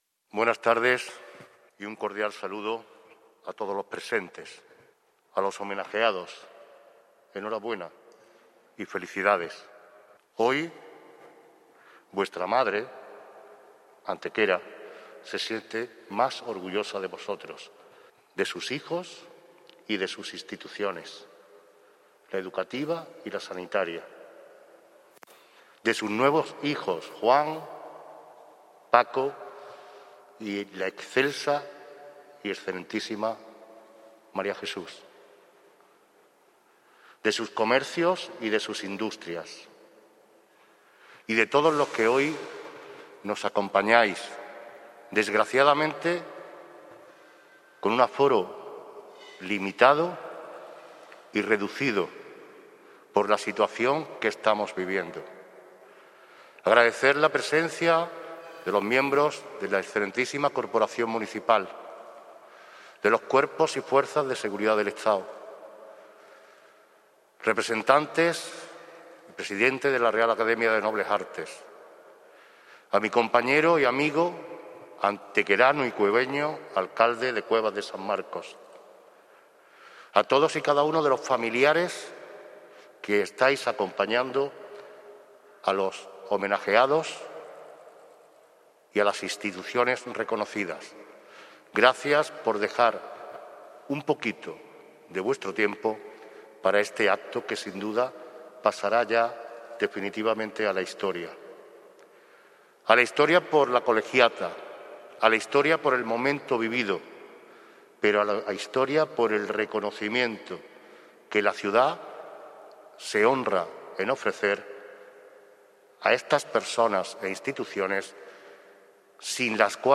La Real Colegiata de Santa María acoge un emotivo acto de entrega de Distinciones Municipales de Honor en el Día en el que se cumplen 610 años de la toma de Antequera por parte del Infante Don Fernando
Cortes de voz